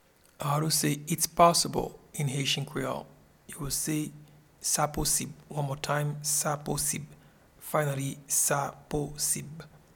Pronunciation and Transcript:
Its-possible-in-Haitian-Creole-Sa-posib.mp3